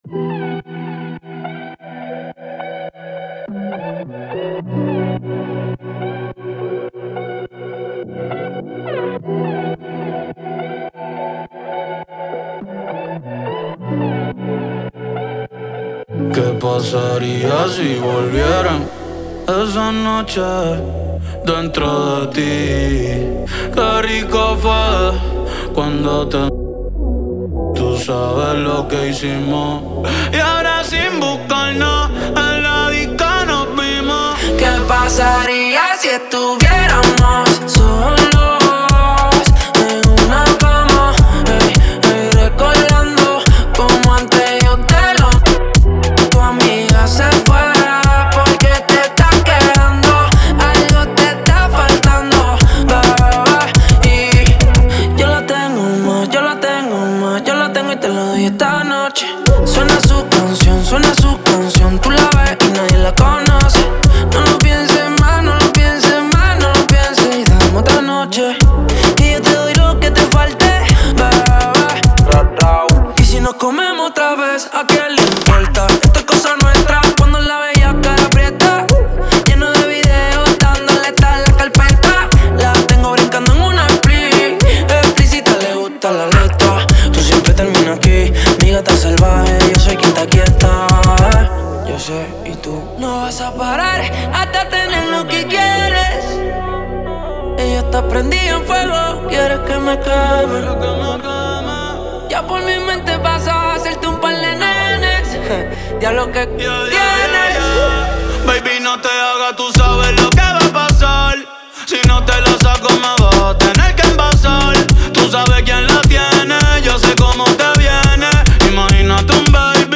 Genre: Ton Ton.